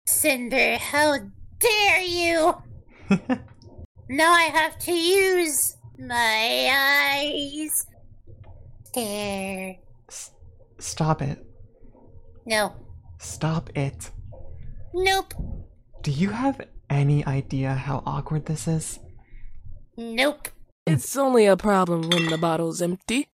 Coming back with low effort VA’ing lol.